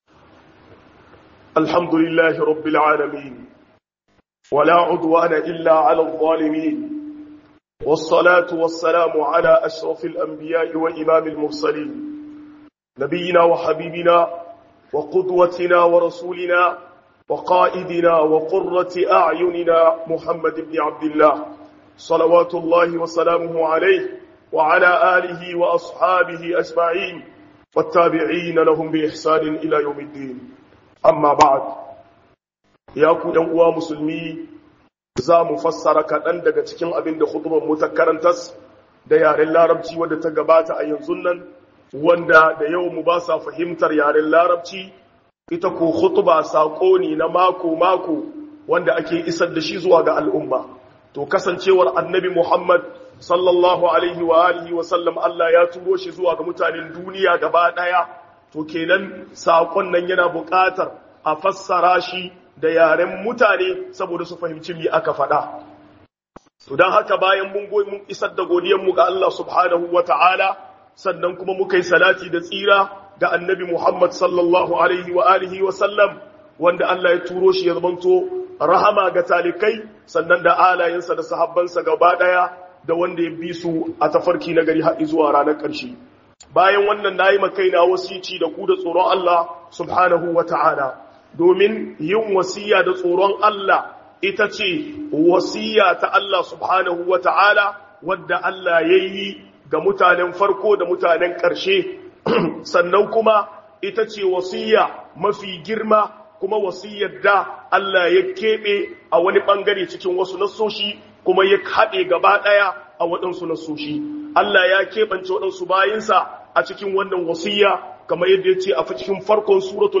KHUƊUBAR JUMA’A (HAUSA)